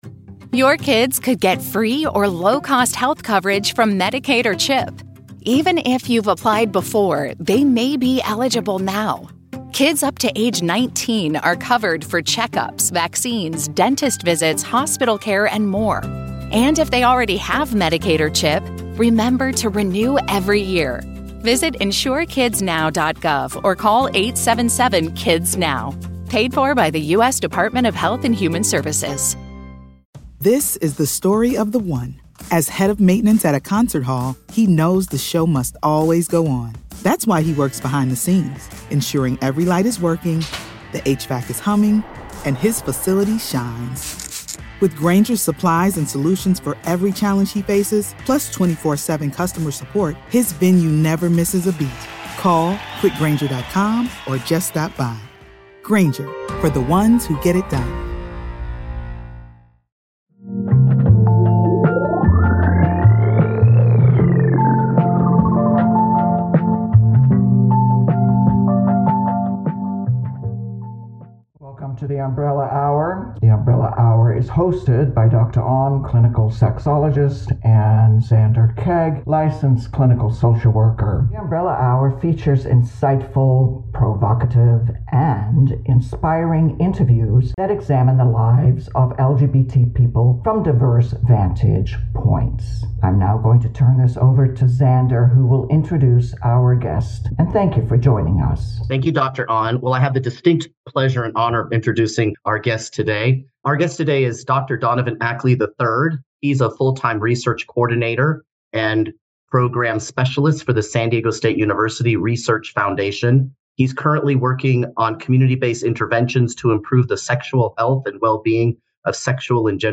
features insightful, provocative, and inspiring interviews that examine the lives of LGBT people from diverse vantage points.